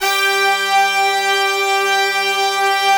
G4 POP BRA.wav